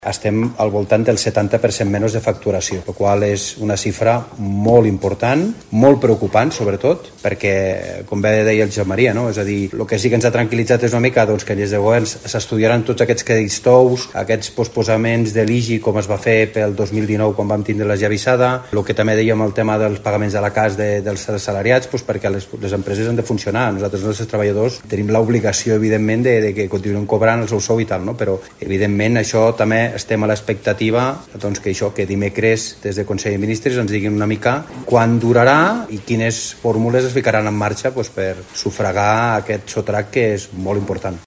en una roda de premsa posterior a la reunió de l’òrgan.